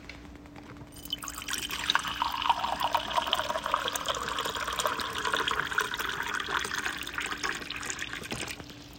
Liquid-Dispensing like 0